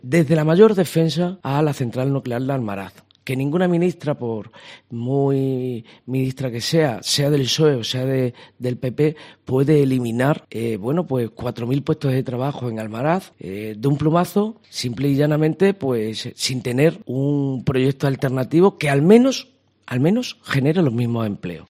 En la entrevista que concedió a COPE la pasada semana, en la que dejó varios titulares, aseguró que iba a poner por delante a Extremadura.